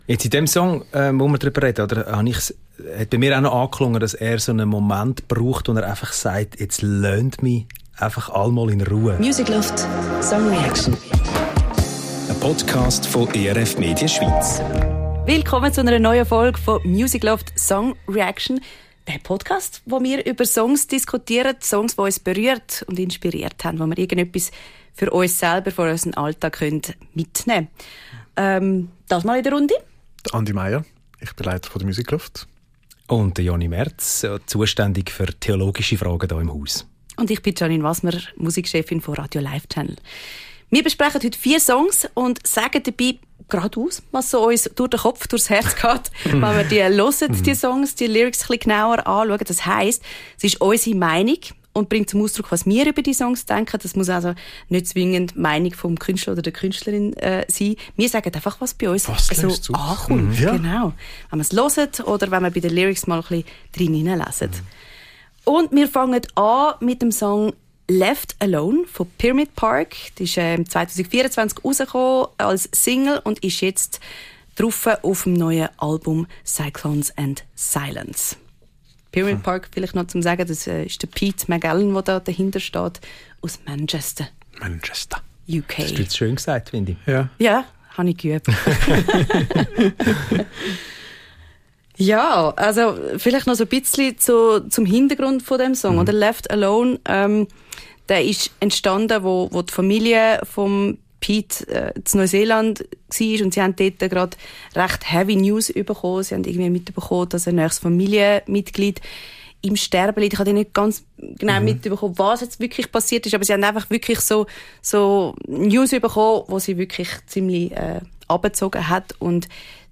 Beschreibung vor 1 Jahr Zu Dritt besprechen wir die Songs, die uns gerade inspirieren und zum Nachdenken anregen. Da geht es um Trauer, und verschiedene Wege damit umzugehen.